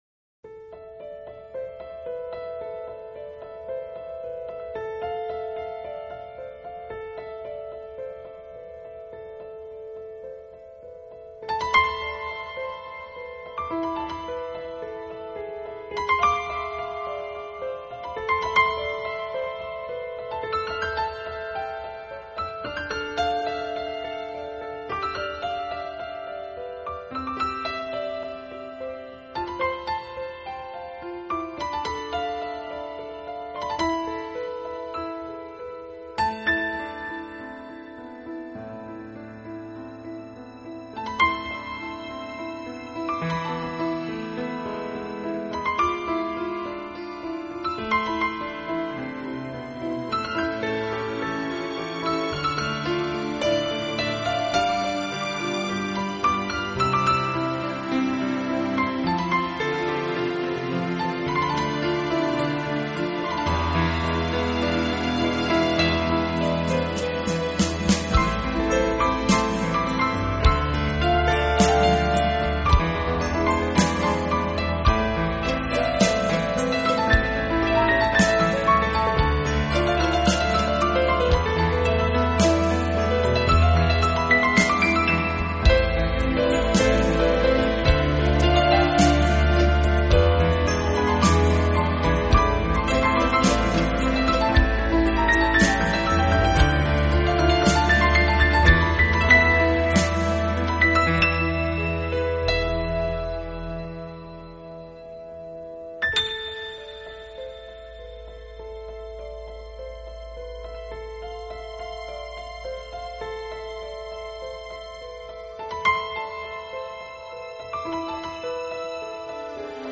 音乐风格：钢琴